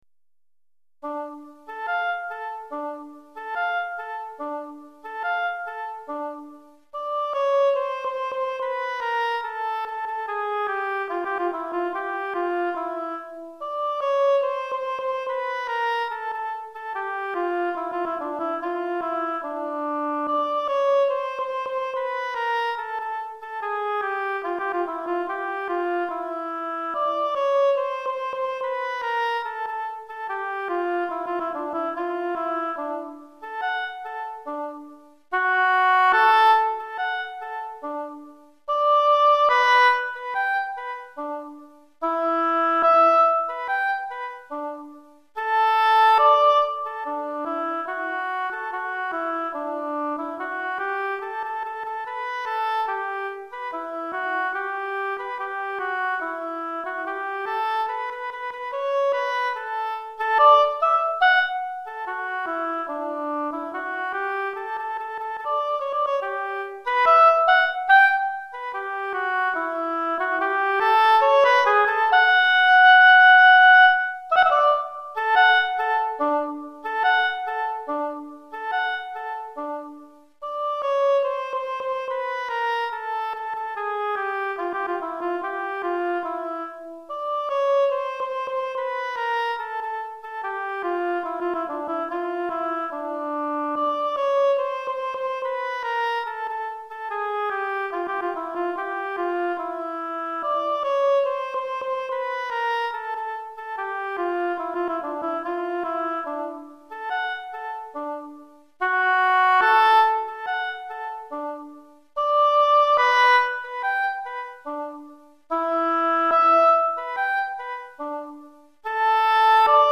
Hautbois Solo